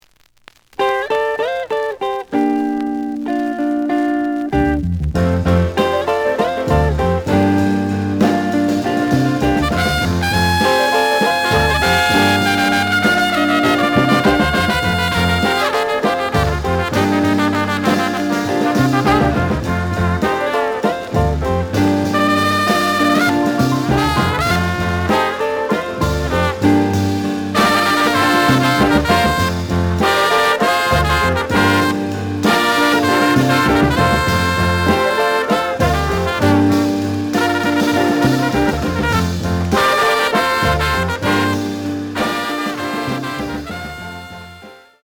The audio sample is recorded from the actual item.
●Genre: Latin Jazz